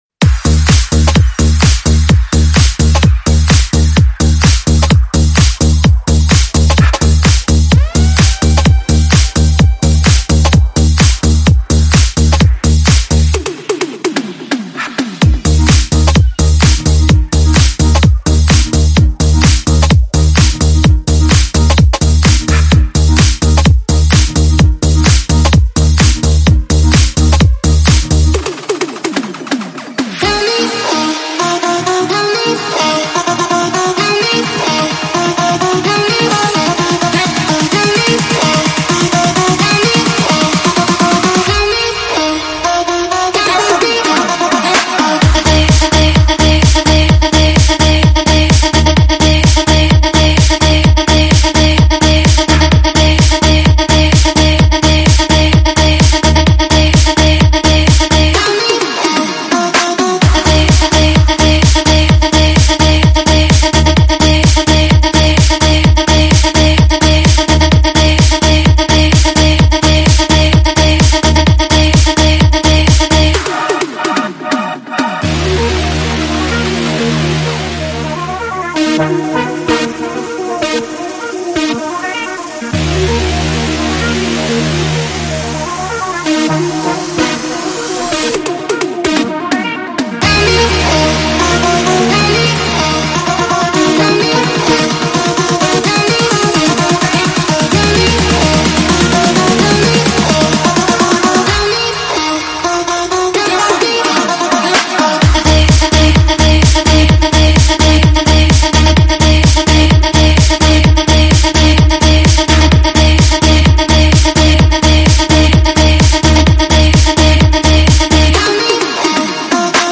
试听文件为低音质